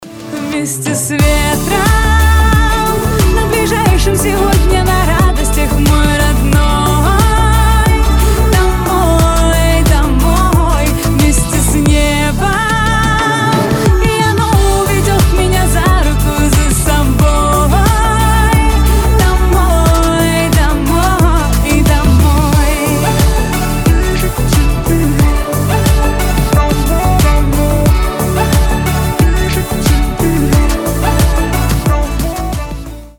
• Качество: 320, Stereo
поп
Electronic
добрые
теплые